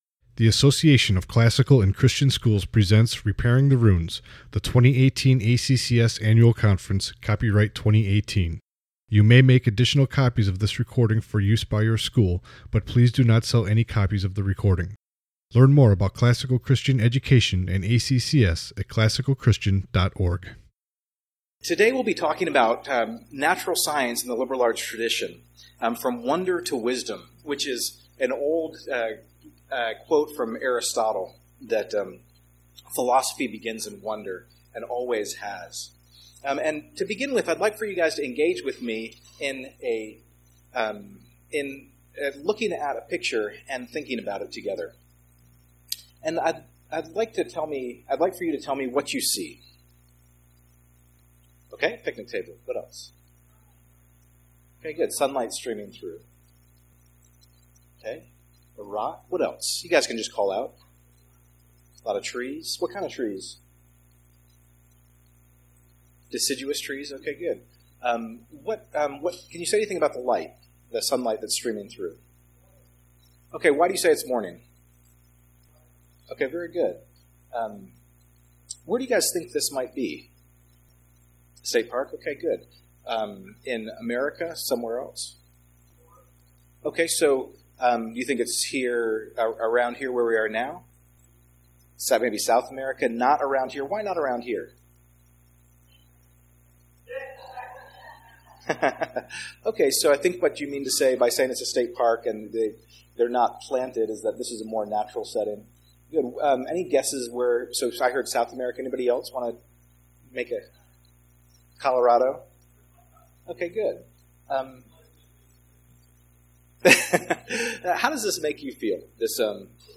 2018 Workshop Talk | 57:15 | All Grade Levels, Science